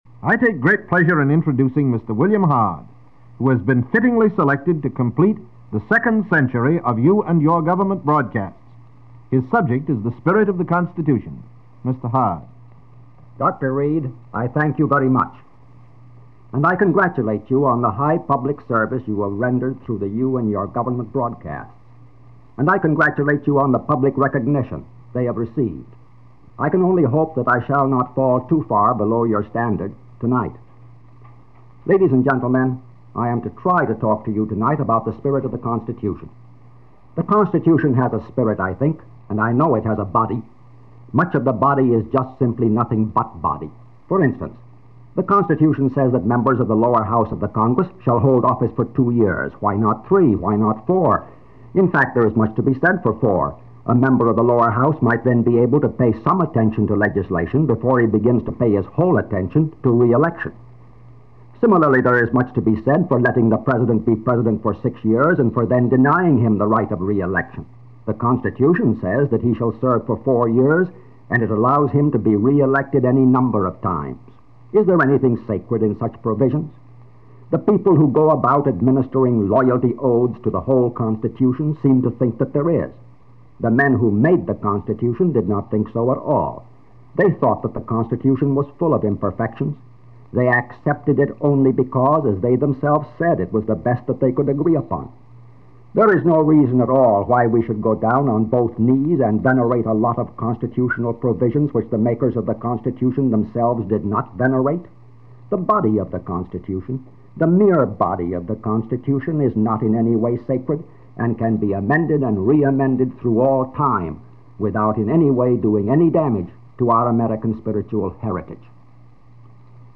William Hard: Essay on the Constitution – You And Your Government radio series – March 31, 1936